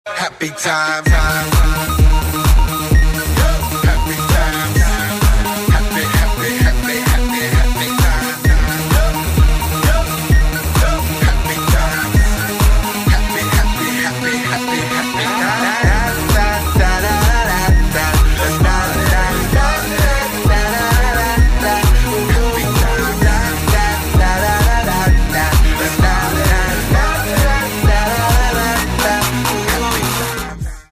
• Качество: 128, Stereo
позитивные
мужской вокал
громкие
веселые
dance
Electronic
EDM
электронная музыка
club